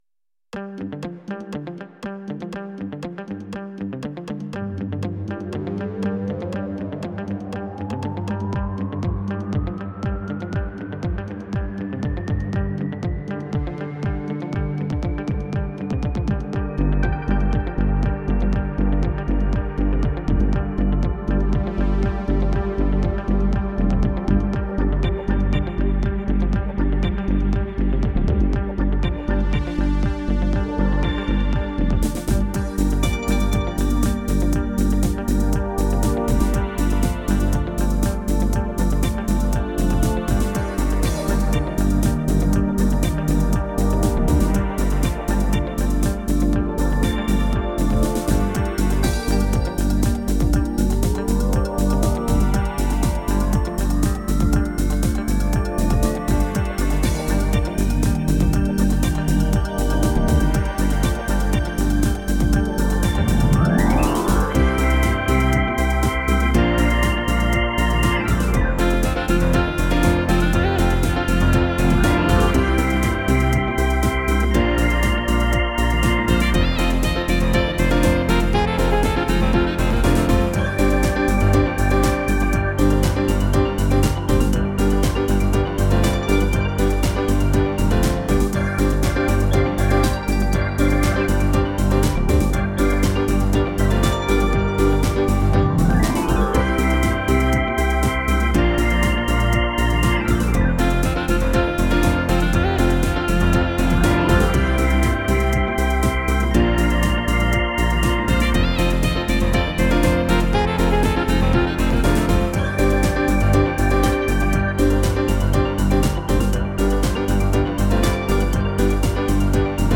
Audio with Lead-solo (part of the pack):